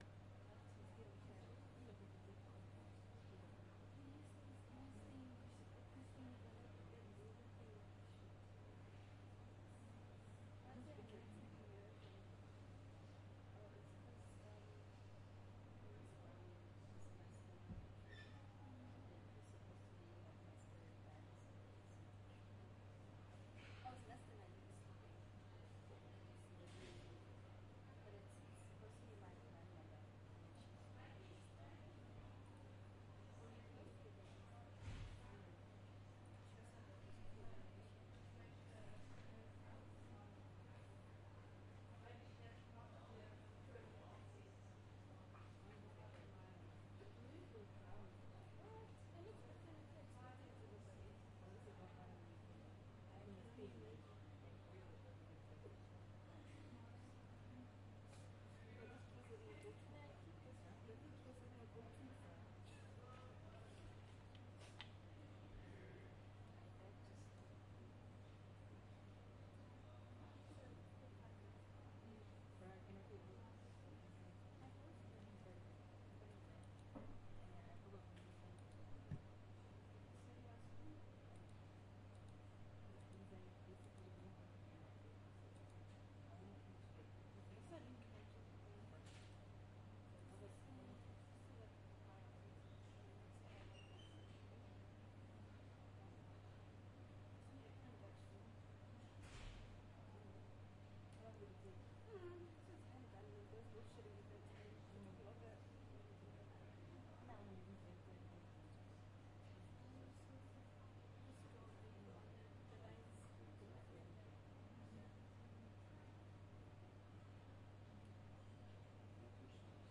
学院图书馆的氛围 - 声音 - 淘声网 - 免费音效素材资源|视频游戏配乐下载
大学图书馆氛围：大学图书馆的氛围。安静，柔和的说话和窃窃私语。可以用作图书馆的氛围或某个需要保持安静的地方。记录在tascam DR40上。